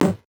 Index of /musicradar/8-bit-bonanza-samples/VocoBit Hits
CS_VocoBitC_Hit-12.wav